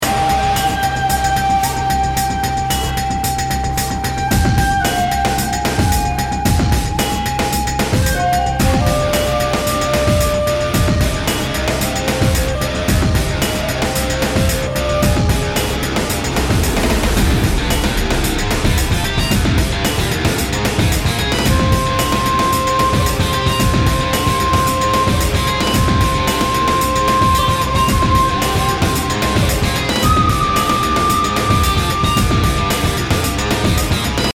BPM 112